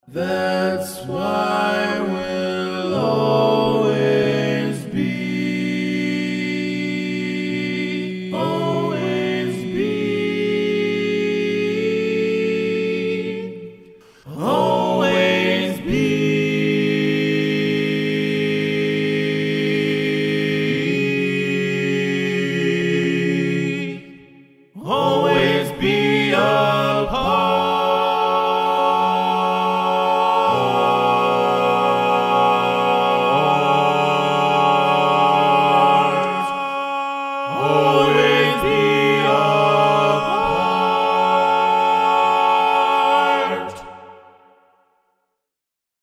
Key written in: F# Minor
Type: Barbershop